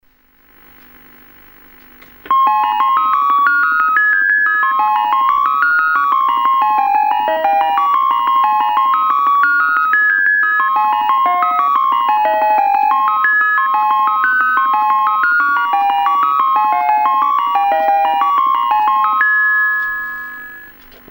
left the recorder in front of the TV so its not HD quality.
Crude recordings of my endeavours today
Variation on the same theme [ Dixons Music Player ]